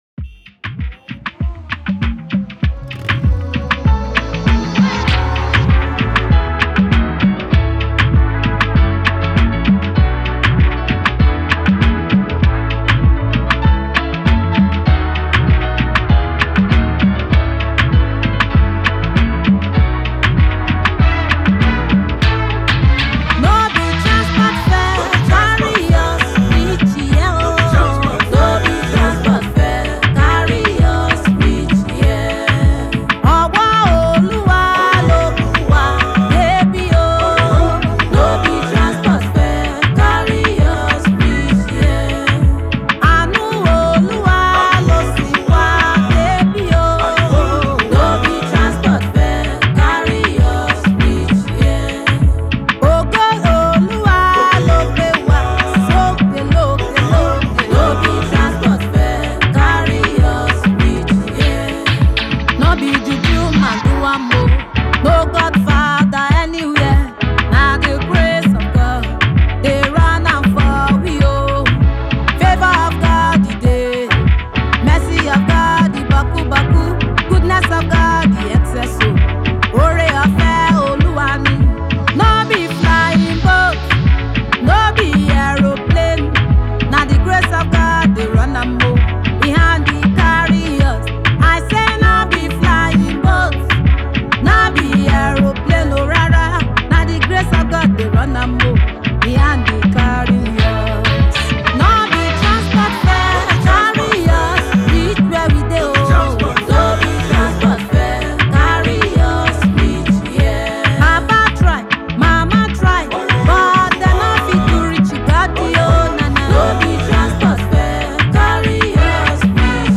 A genuine example of worship in both spirit and truth.